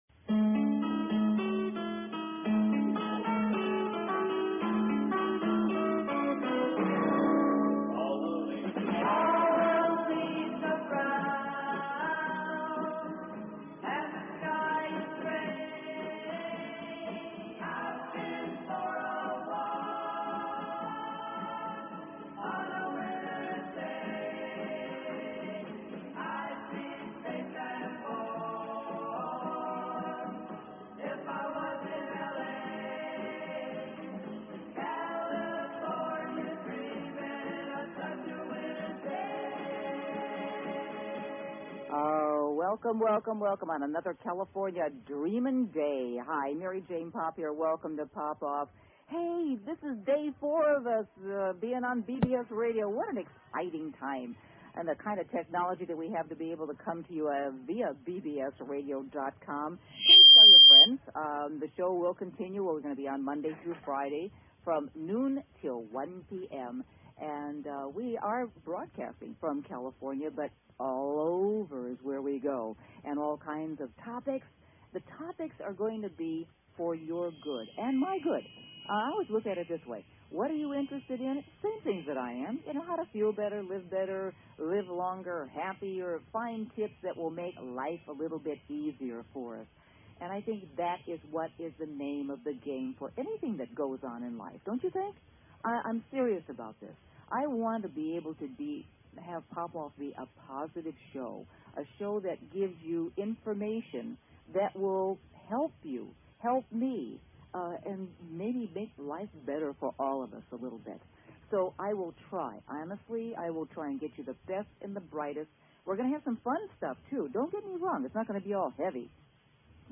Talk Show Episode, Audio Podcast, Poppoff and Courtesy of BBS Radio on , show guests , about , categorized as
A fast-paced two hour Magazine-style Show dedicated to keeping you on the cutting edge of today's hot button issues.